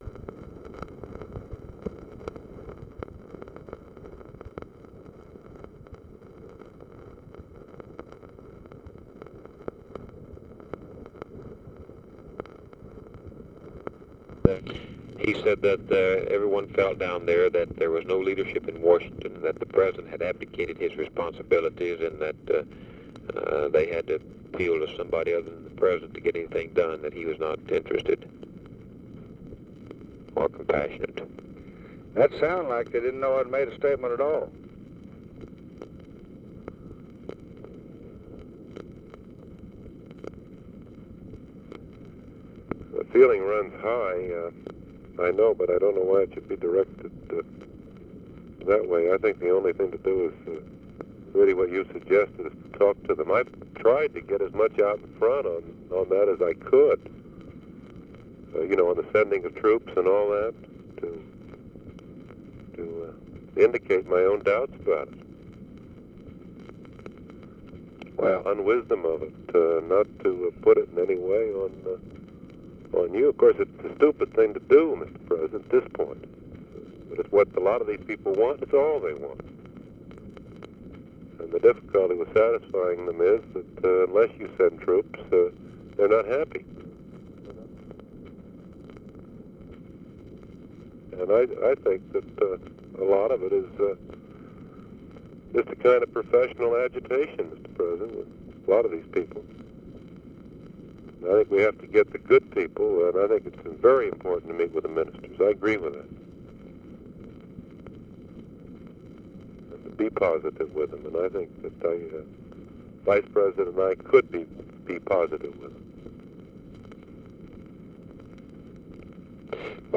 Conversation with NICHOLAS KATZENBACH and BILL MOYERS, March 11, 1965
Secret White House Tapes